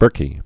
(bûrkē) Scots